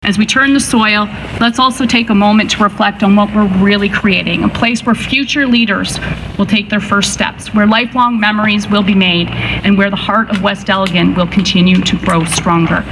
Groundbreaking ceremony held at West Elgin Public School
MPP Bobbi Ann Brady was also on hand, speaking of all the hard work that has been going on behind the scenes and what that means for kids in our community moving forward.